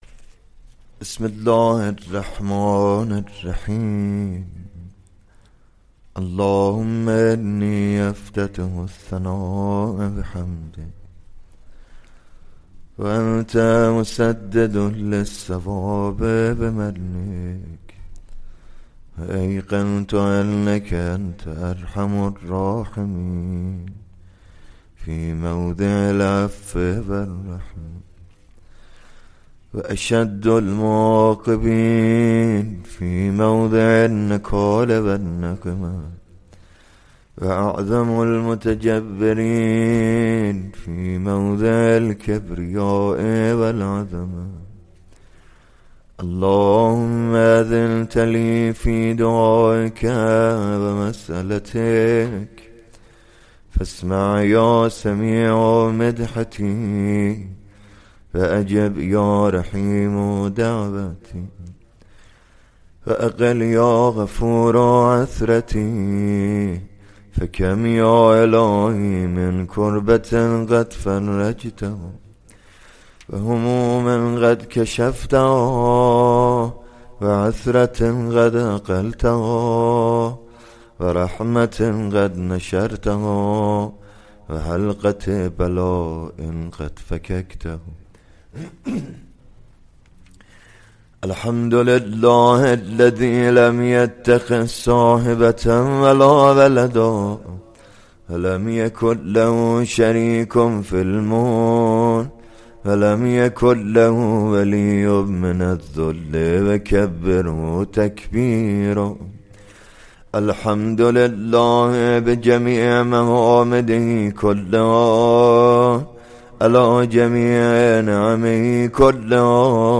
مَدرَس مهديه باقرالعلوم - دعای افتتاح و روضه حضرت خدیجه(س) ۱۰ شهر رمضان المبارک ۱۴۴۴
دسته بندی : مجالس روضه و مدح آل الله الاطهار علیهم صلوات الله الملک الجبار